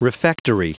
Prononciation du mot refectory en anglais (fichier audio)
Prononciation du mot : refectory